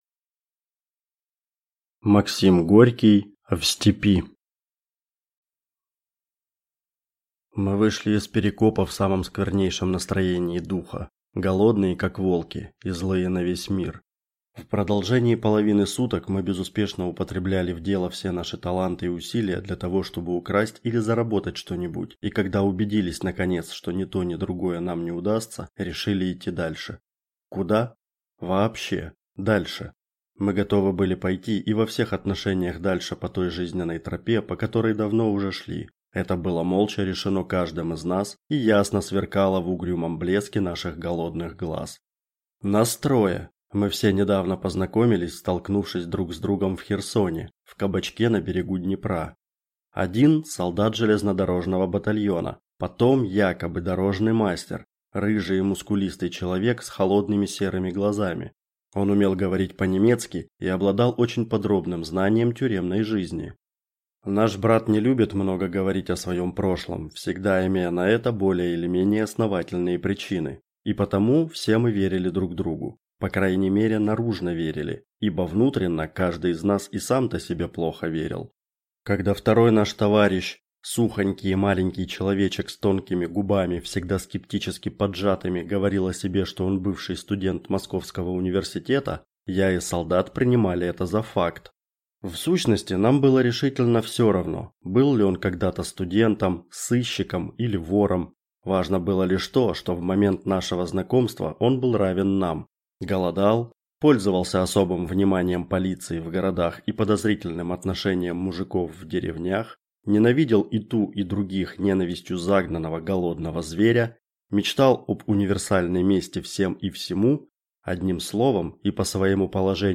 Читает аудиокнигу